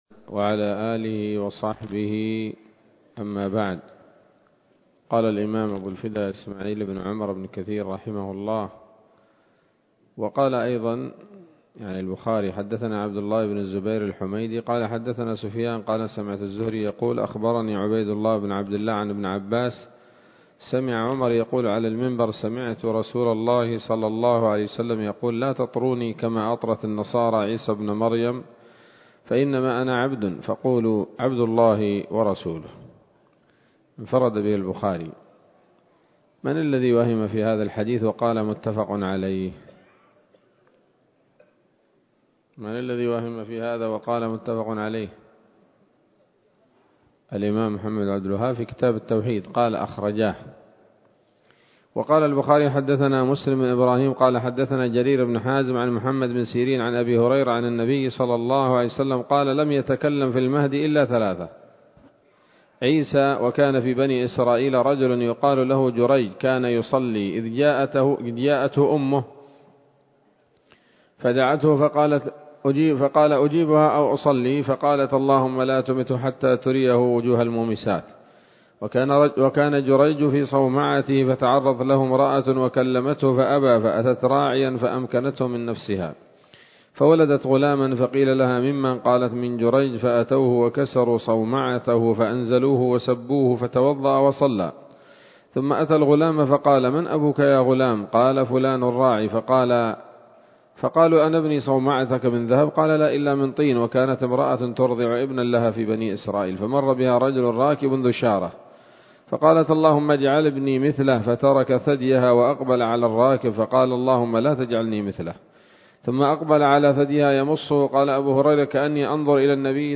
‌‌الدرس الرابع والخمسون بعد المائة من قصص الأنبياء لابن كثير رحمه الله تعالى